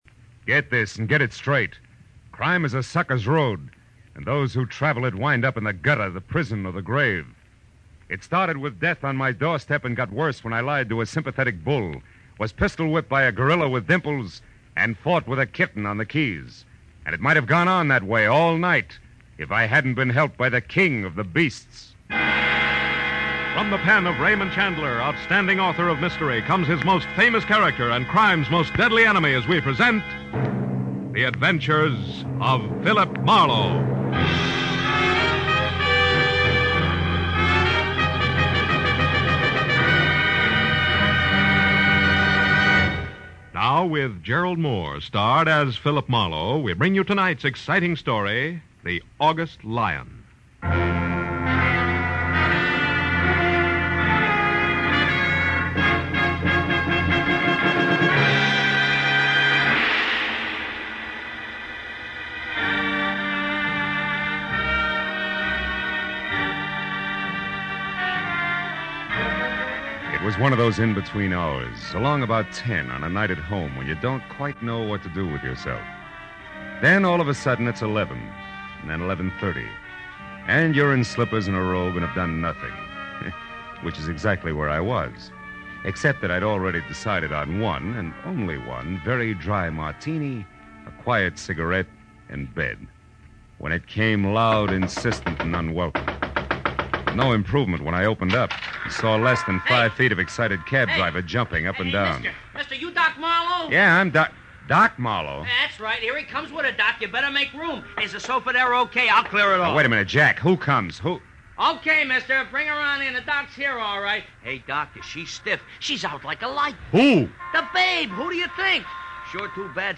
In 1948, the series moved to CBS, where it was called The Adventure of Philip Marlowe, with Gerald Mohr playing Marlowe.